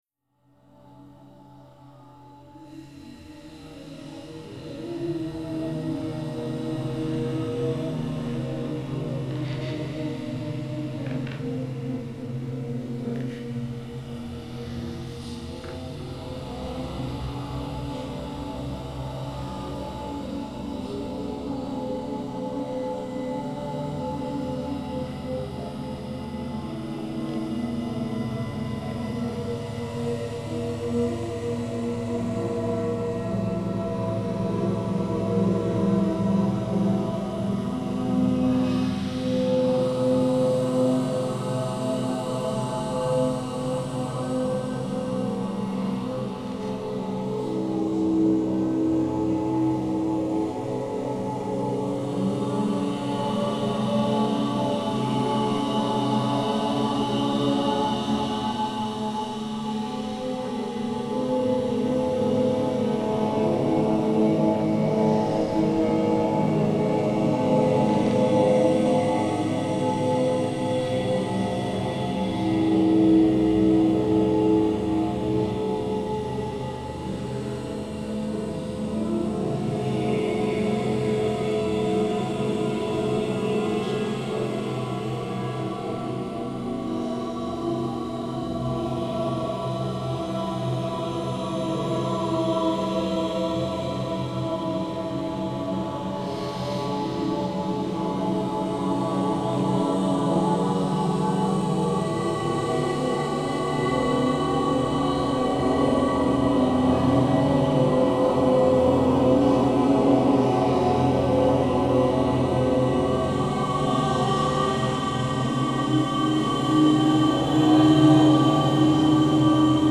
Dance ensemble
Postproduced in 2010]